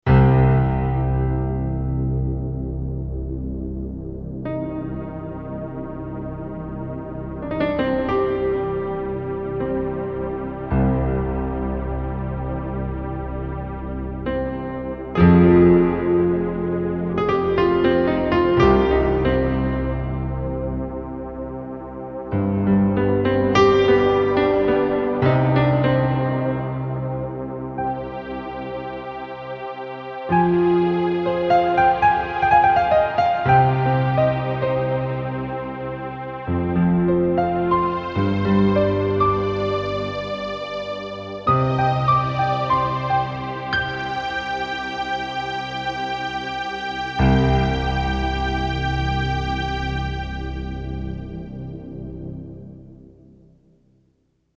Piano Evolution is an exciting set of new piano sounds for your keyboard that are powered by 32MB of high quality stereo Concert Piano samples that span the entire range of your keyboard. Thanks to Piano Evolutions dual layer multisamples, the sound will respond in great detail when played even with the slightest touch, right up to the most dynamic heights.